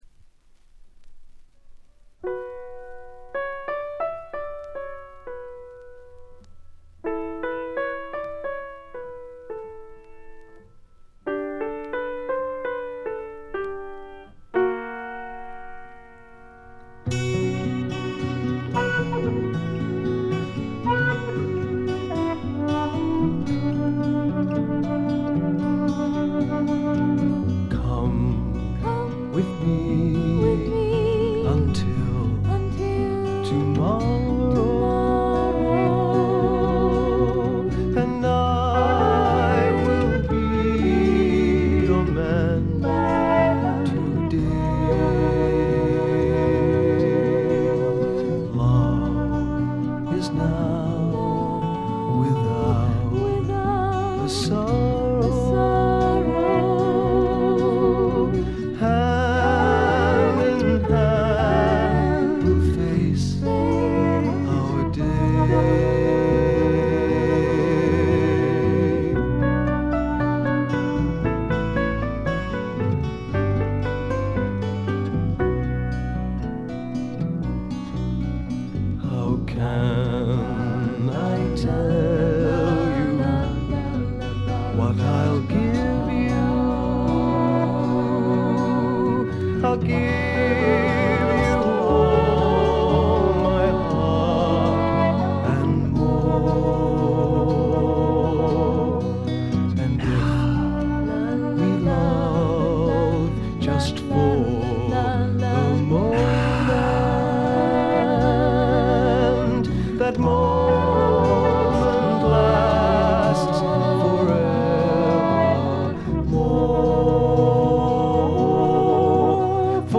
ごくわずかなノイズ感のみ。
で内容はというと英米の良さを併せ持った素晴らしすぎるフォーク／フォークロックです。
試聴曲は現品からの取り込み音源です。